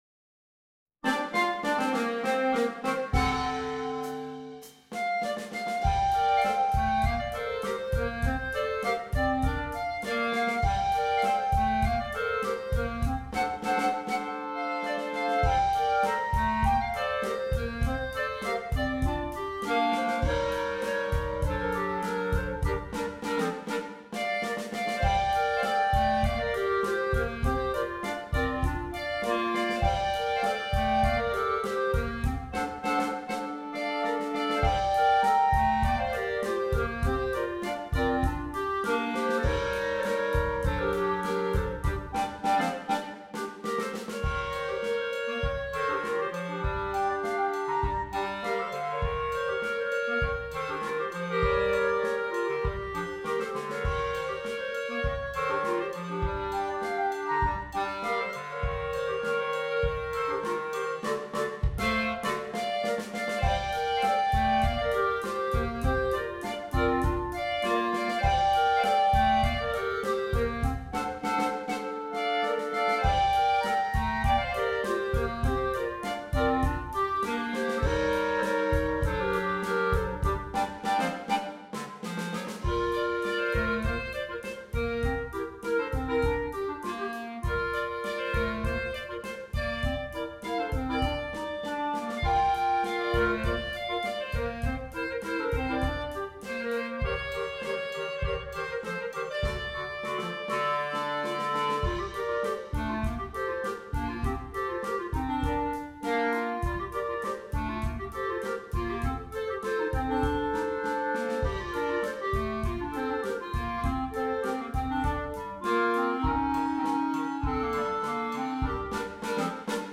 Voicing: Bb Clarinet Quartet